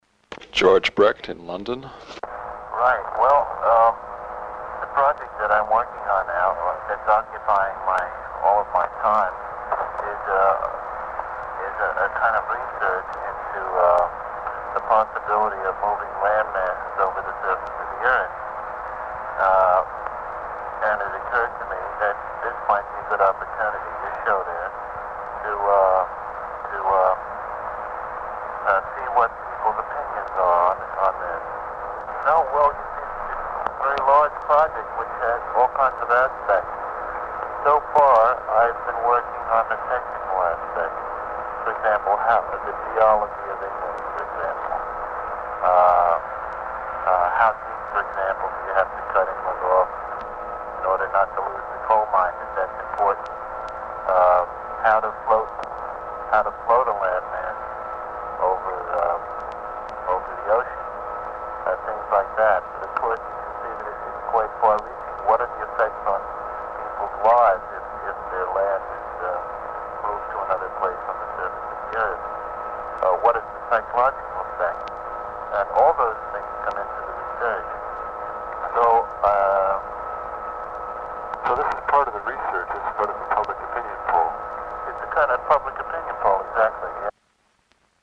audio extrait du vinyle de 1969